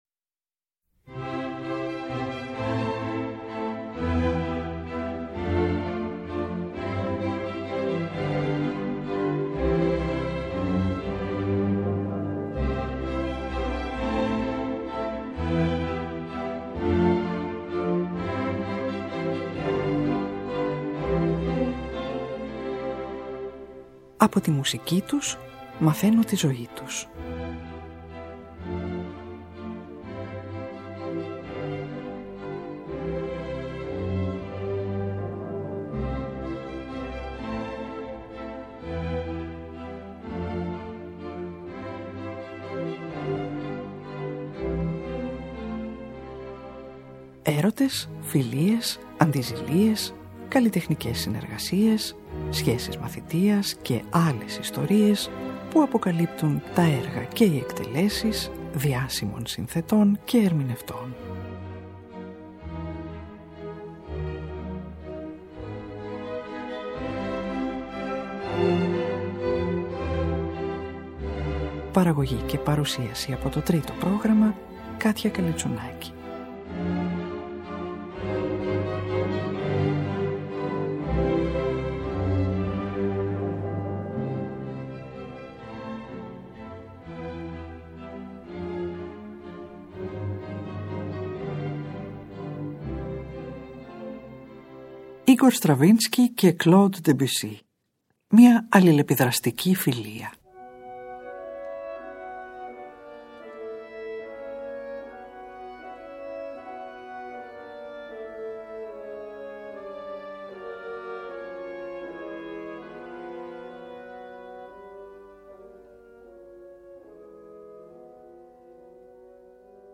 Ακούγονται 3 μέρη από τα 2 αυτά μπαλέτα του Stravinsky, το μπαλέτο του Debussy “Khamma” στο οποίο ανιχνεύονται επιρροές τους, και, σε μεταγραφή για 4 πιάνα του Marten Bon, το 1ο μέρος του μπαλέτου «Ιεροτελεστία της Άνοιξης» που ο Stravinsky έπαιξε με τον Debussy για πιάνο 4 χέρια το 1912, πριν από την Παρισινή πρεμιέρα του το 1913, στο οποίο εντοπίζονται αναφορές σε έργα του Γάλλου συνθέτη.